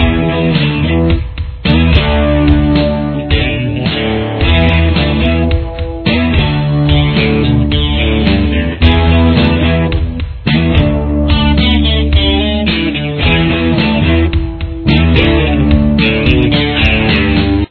Main Riff
cocaine_2_loop.mp3